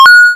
pickupCoin.wav